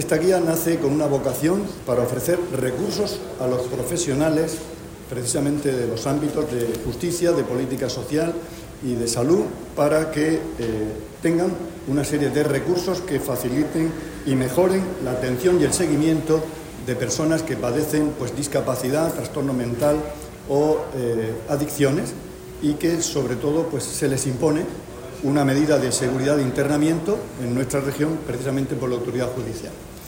Declaraciones del consejero de Salud, Juan José Pedreño, en la presentación de la guía para mejorar la atención sociosanitaria a personas inimputables.